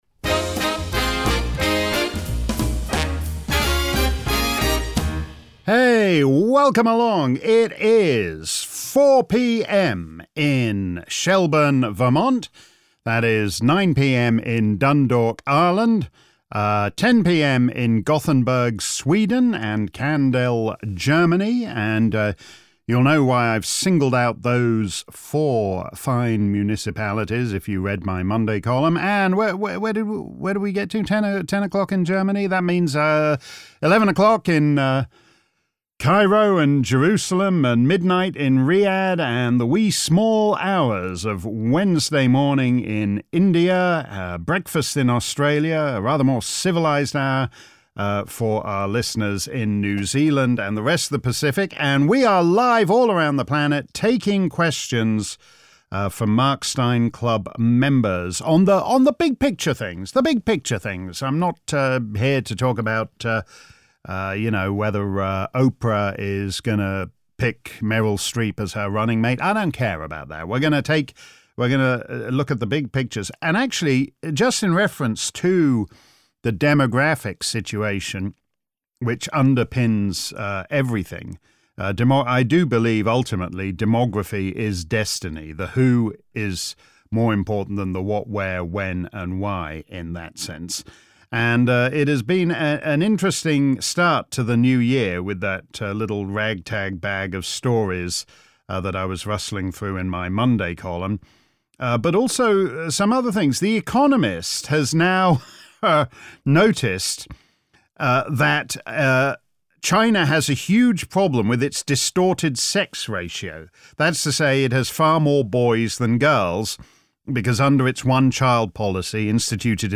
If you missed our livestream Clubland Q&A earlier today, here's the action replay. Simply click above for an hour of my answers to questions from Mark Steyn Club members around the planet on various issues related to immigration and identity.